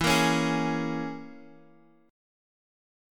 E6b5 chord